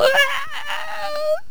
stickfighter_die4.wav